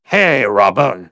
synthetic-wakewords
ovos-tts-plugin-deepponies_Spy_en.wav